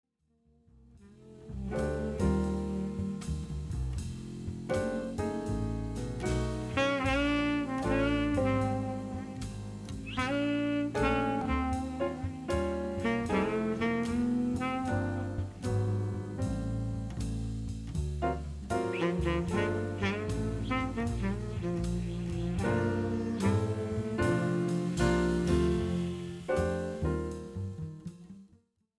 and vibrant Latin and Brazilian jazz melodies
Guitar
Piano
Bass
Drums